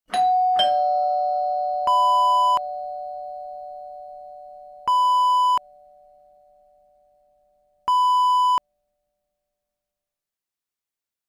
Doorbell ding-dong sound effect .wav #1
Description: Doorbell ding-dong
Properties: 48.000 kHz 16-bit Stereo
A beep sound is embedded in the audio preview file but it is not present in the high resolution downloadable wav file.
doorbell-preview-1.mp3